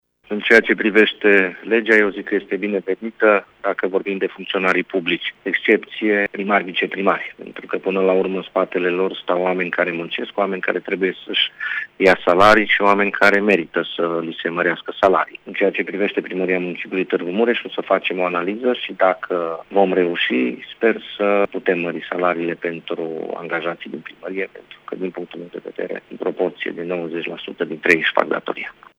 Viceprimarul municipiului Tîrgu-Mureş, Claudiu Maior, a spus că măsura este binevenită şi că se va face o analiză a bugetului pentru a vedea în ce măsura va putea fi pusă în practică: